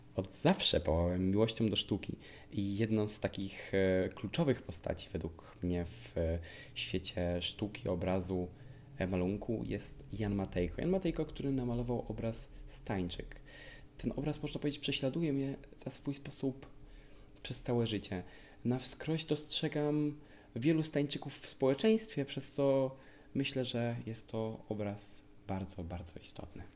Zapytaliśmy studentów, o wspomnienia dzieł, które towarzyszą im przez całe życie i dlaczego są dla nich tak ważne: